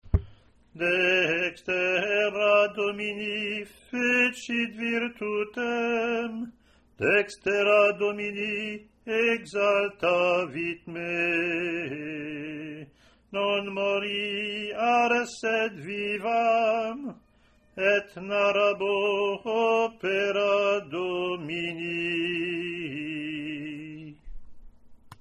USING PSALM TONE 7 WITH THE “ROSSINI PROPER”
epiph3-r-offertory.mp3